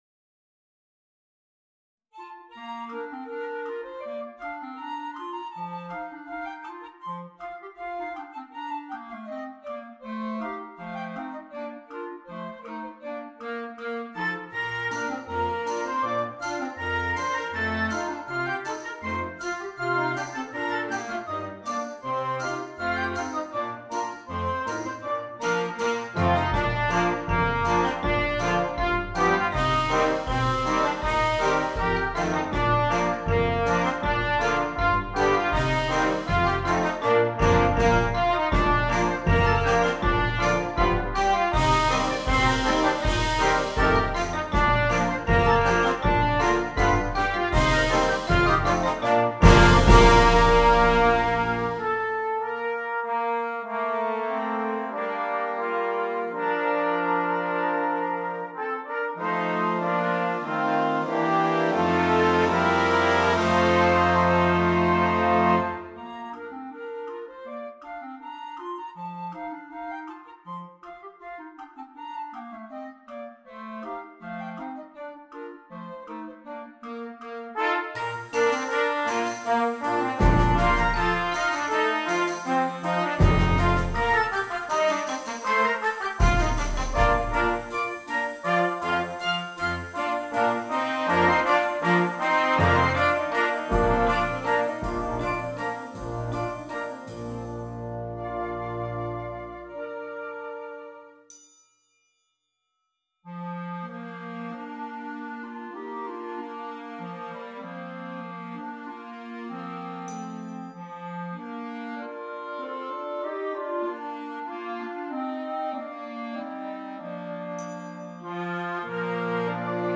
Concert Band
The contrasting lyrical middle section is original